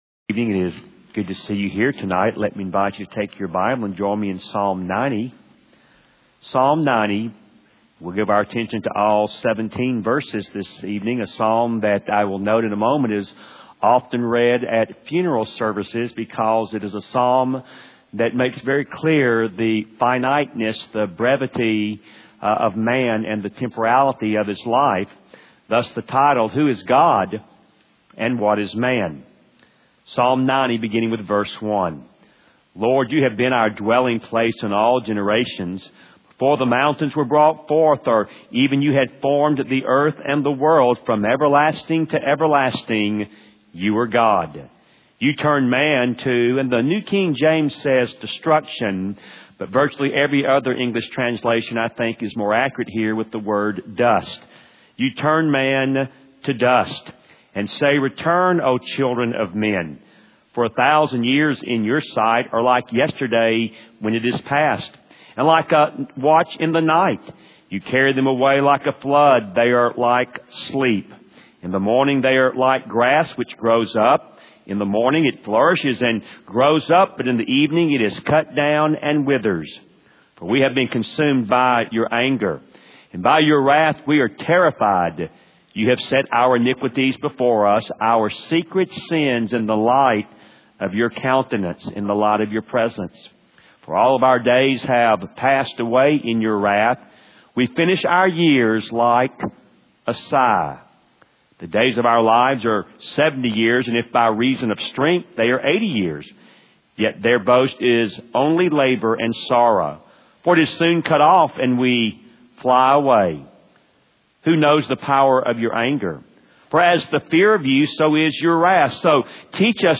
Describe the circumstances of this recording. Part of a series entitled “Through the Psalms” delivered at Wake Cross Roads Baptist Church in Raleigh, NC